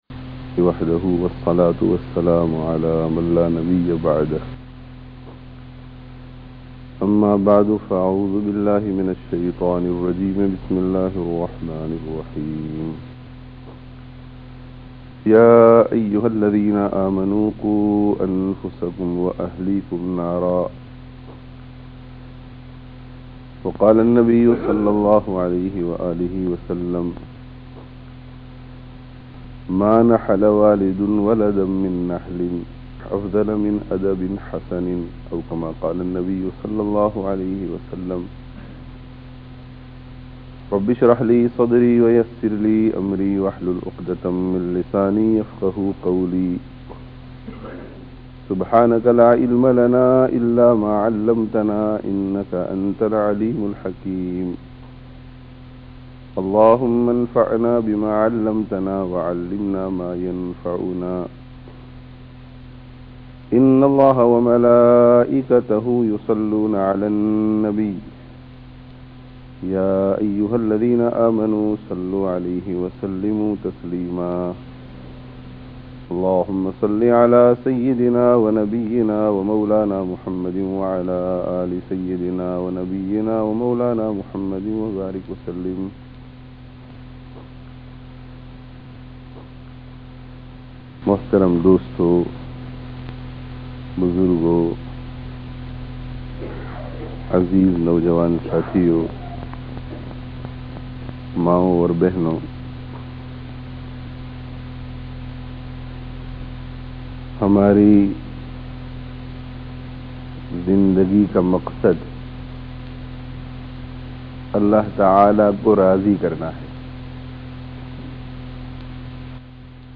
Nayī Nasal kī Fikr Karo (Masjid An Noor, Leicester 10/02/05)